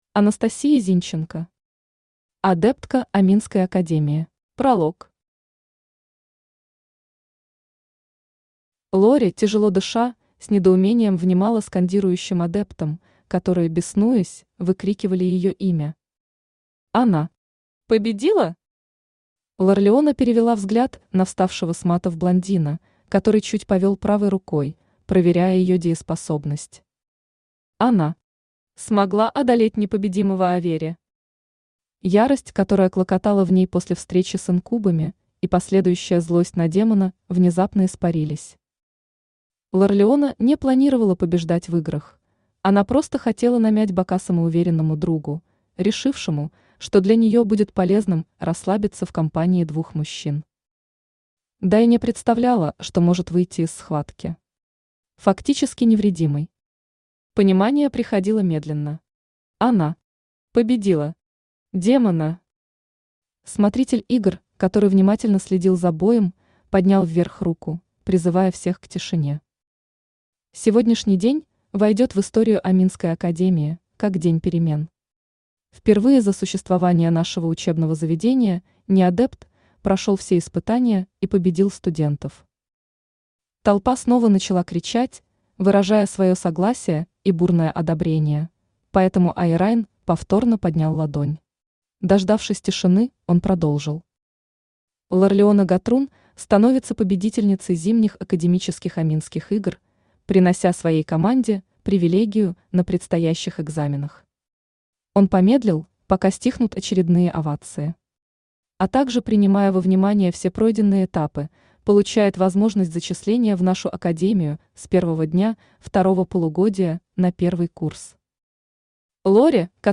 Aудиокнига Адептка Аминской Академии Автор Анастасия Зинченко Читает аудиокнигу Авточтец ЛитРес.